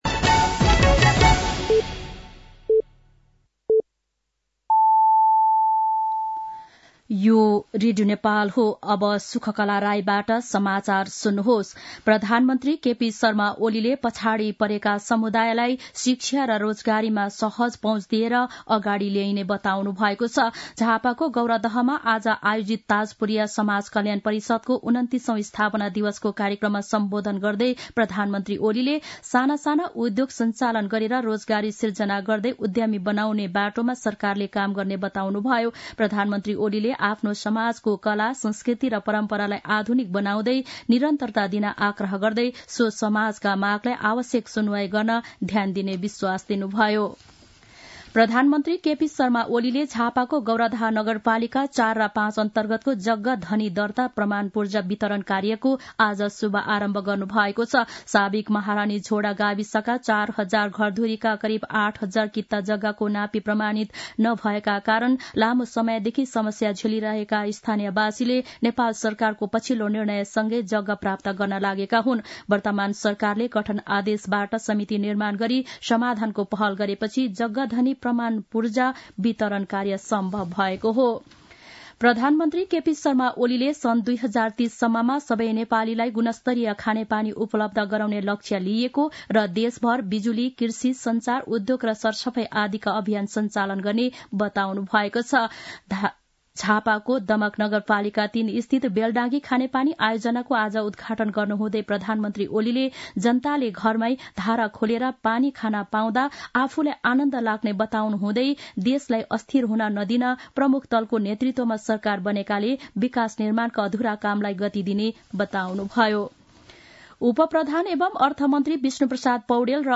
साँझ ५ बजेको नेपाली समाचार : २५ मंसिर , २०८१
5-pm-nepali-news-8-24.mp3